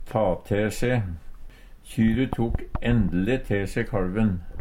ta te se - Numedalsmål (en-US)